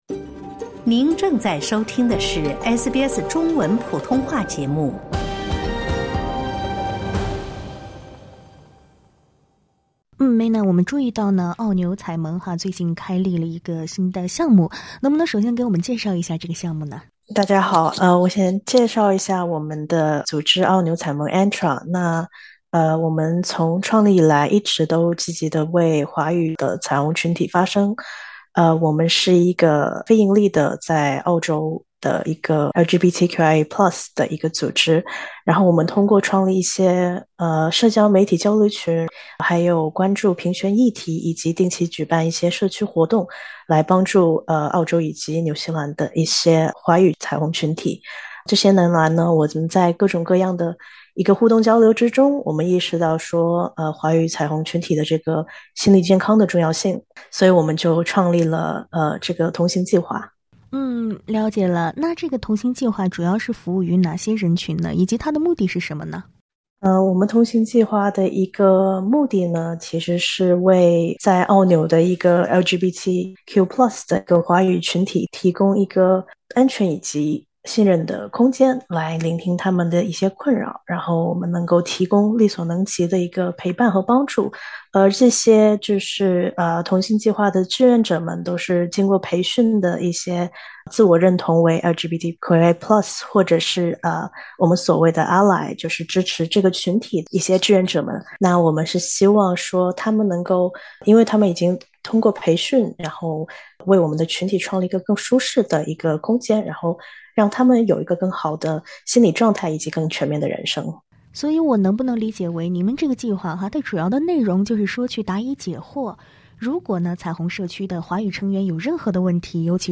（点击上方收听采访） 澳纽彩盟（Australia & New Zealand Tongzhi Rainbow Alliance，ANTRA）开启了一项中文同伴项目，针对华人性少数群体提供支持。